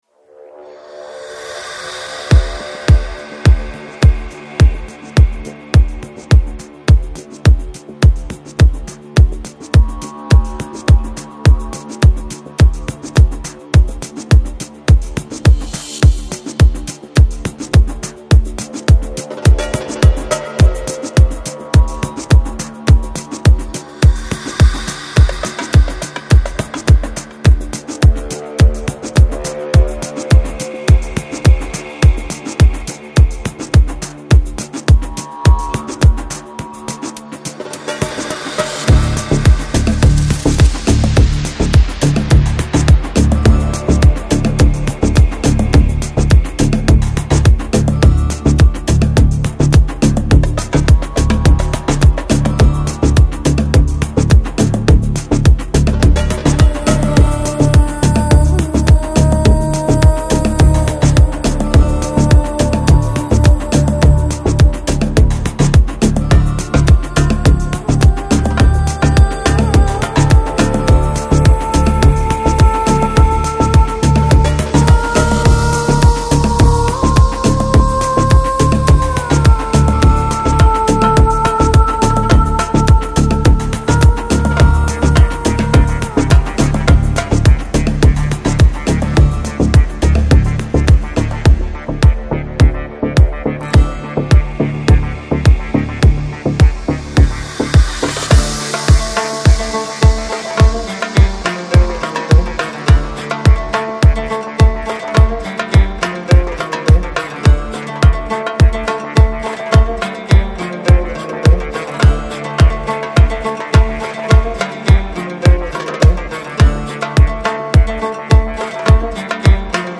Downtempo, Organic House, Lounge, World Год издания